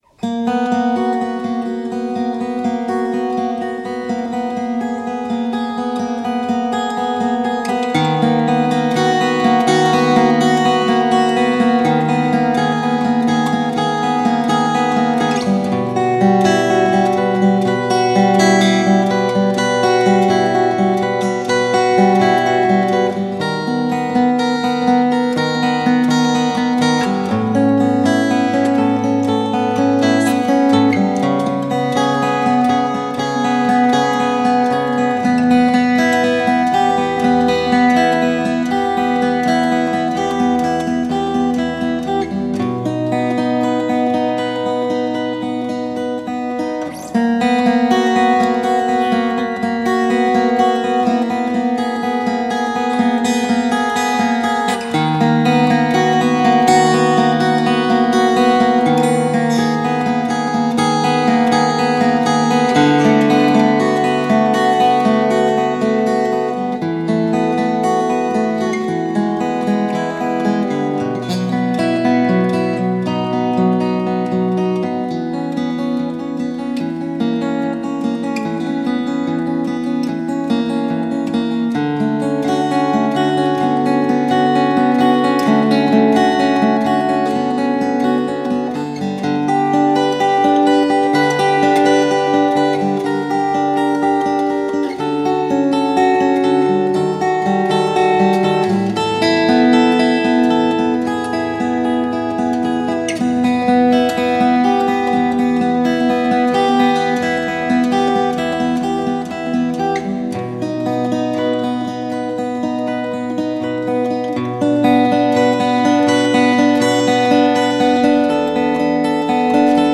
I did a rough recording back in 2011 and can share it here for fun.
Waterfalls Practice Recording 2012